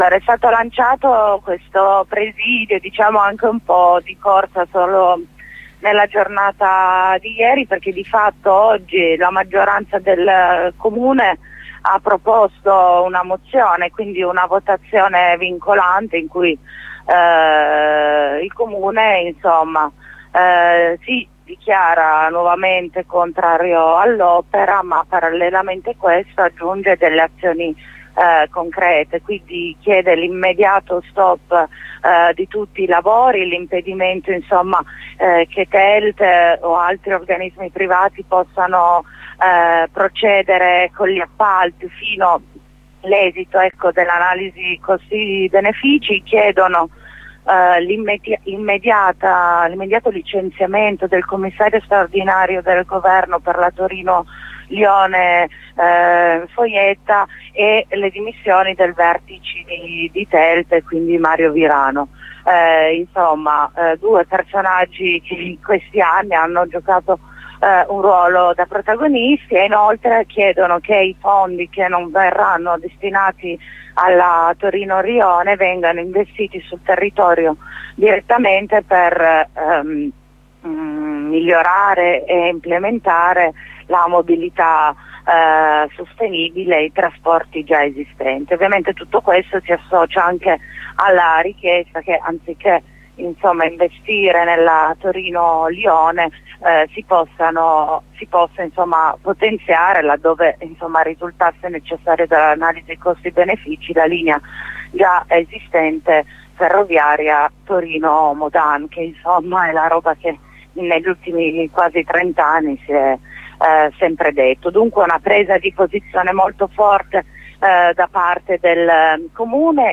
Corrispondenza con una compagna del movimento NoTav
Abbiamo sentito una compagna del movimento NO TAV per lanciare il presidio.